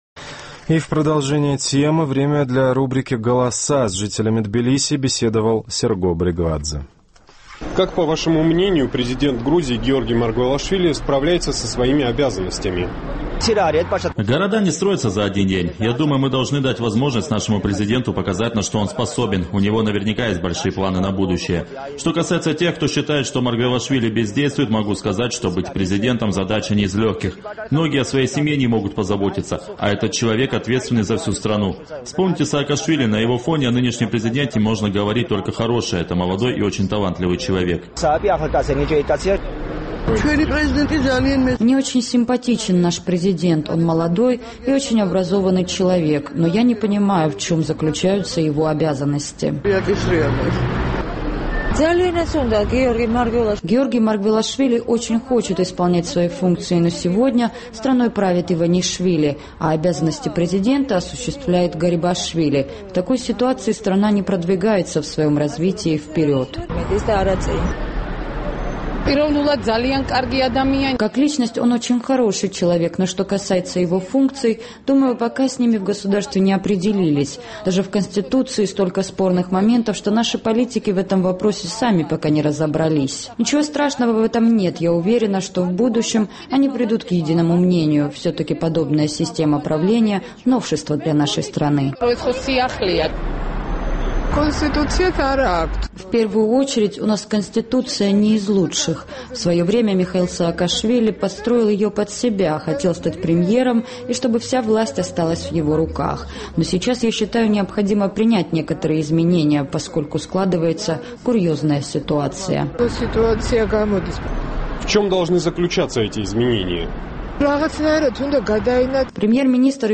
Ровно год назад в Грузии прошли президентские выборы, в ходе которых эту должность занял кандидат от правящей коалиции Георгий Маргвелашвили. Наш корреспондент поинтересовался у жителей Тбилиси о том, как он проявил себя на этом посту.